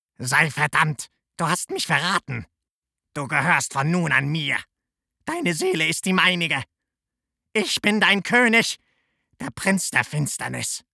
Vielseitig einsetzbare und wandlungsfähige Stimme – von wohlklingend bassig über jugendlich dynamisch bis hin zu Trickstimmen.
Sprechprobe: Sonstiges (Muttersprache):